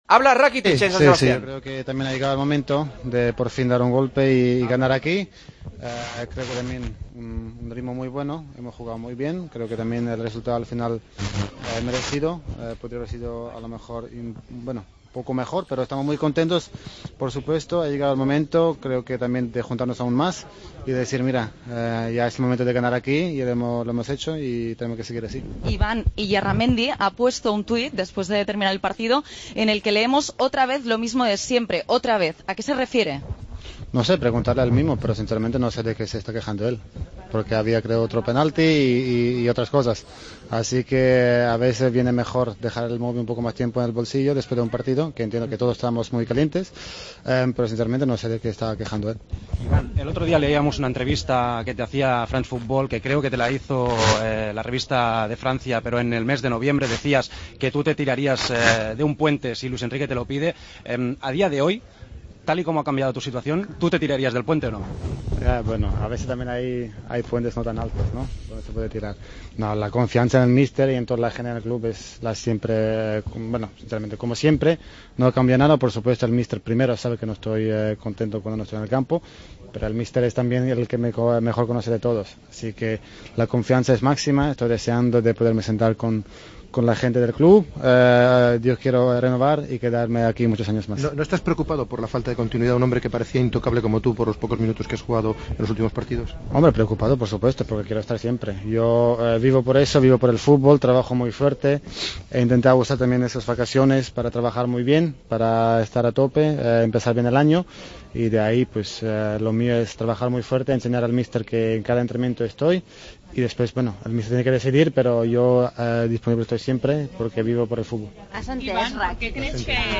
El centrocampista del Barcelona valoró el tuit de Illarramendi, sobre la actuación arbitral, y su futuro en el conjunto azulgrana.